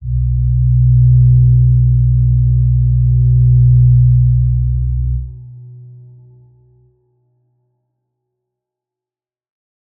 G_Crystal-B2-f.wav